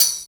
88 HAT+TMB-L.wav